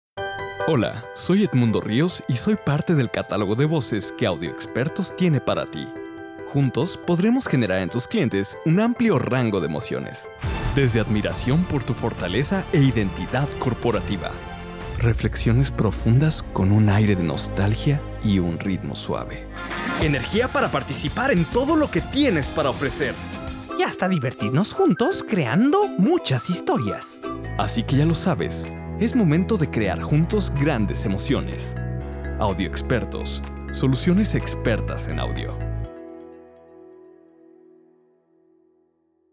Locutores
Rango de Voz: 28 a 38 años